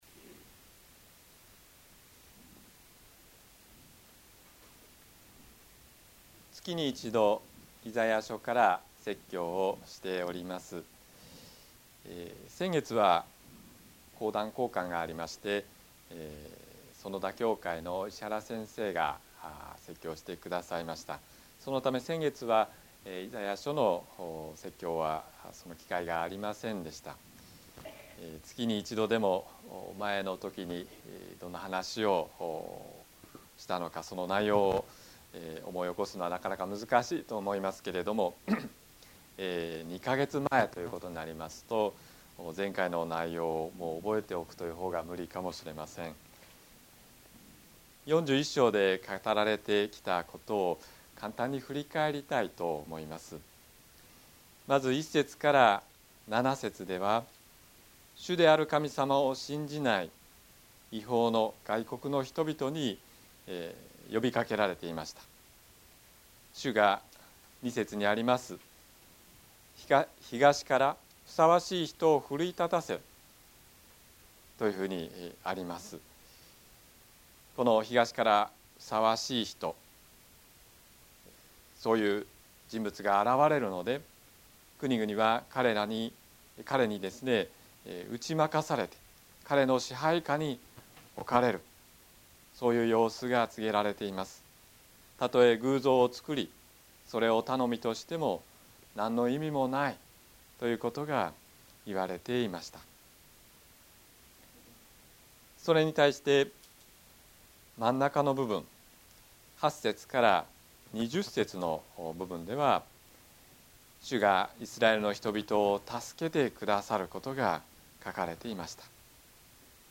説教アーカイブ。
日曜 朝の礼拝